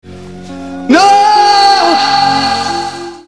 But, as expected, Billy's fear of guns is accompanied by a fear of death, & upon verifying that he has, indeed, killed a kid, he lets out a rather laughable